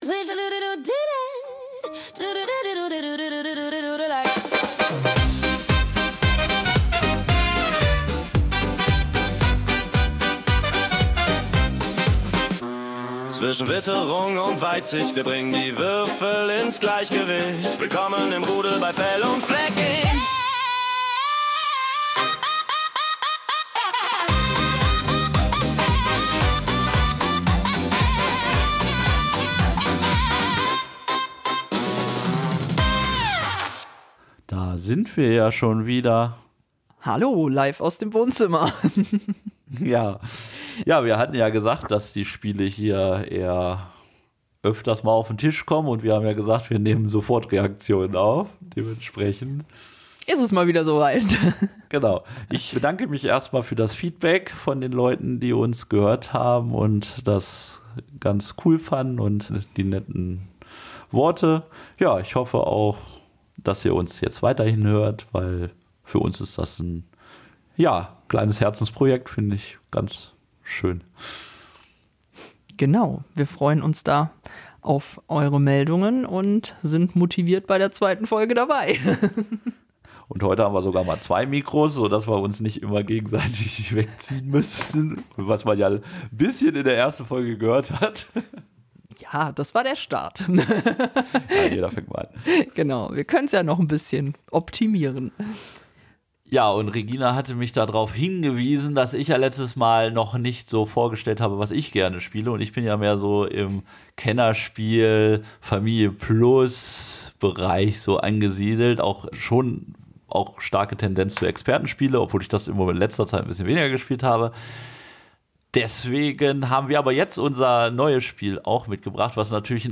Beschreibung vor 2 Monaten In der neuesten Episode unseres Herzensprojekts nehmen wir uns die Zeit, das Spiel „Daydream“ (Asmodee) im Detail zu besprechen. Aus unserem gemütlichen Wohnzimmer heraus (diesmal mit zwei Mikros!) sprechen wir über die erste Spielerfahrung, die wir mit diesem entspannten Roll-and-Write-Spiel gemacht haben.